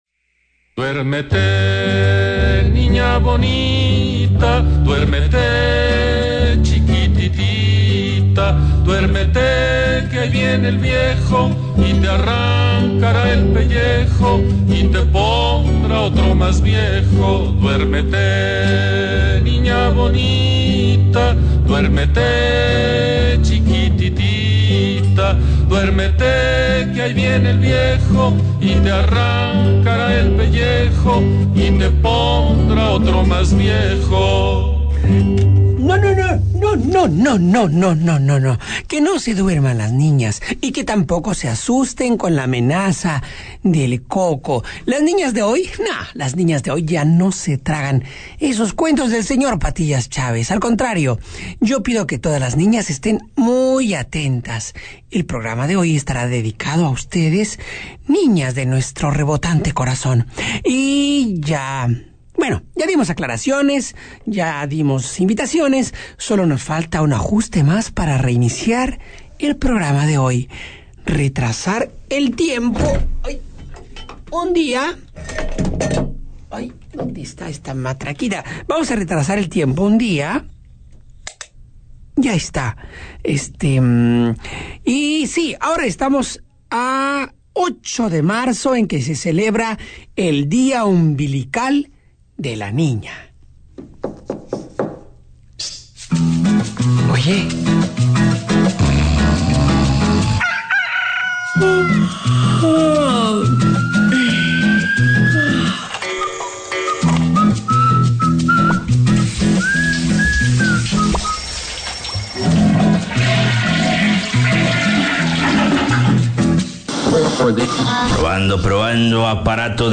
Que todas las niñas estén muy atentas, que muchas canciones van a desfilar.